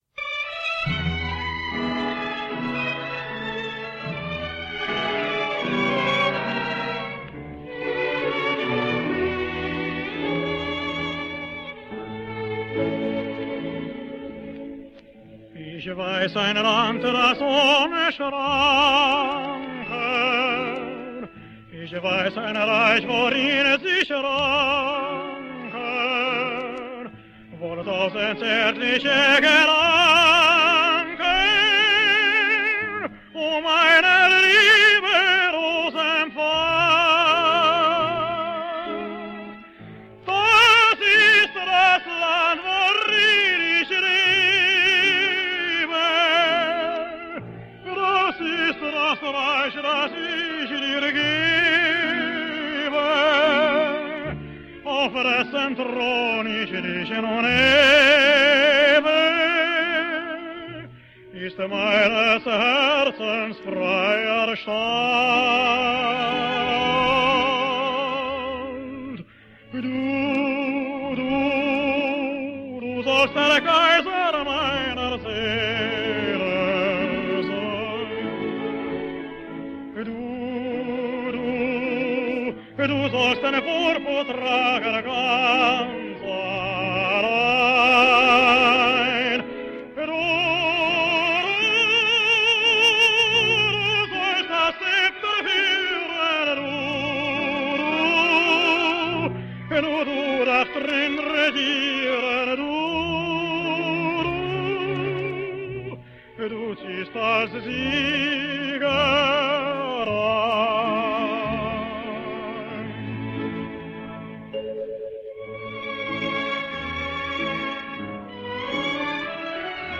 Here he is in operetta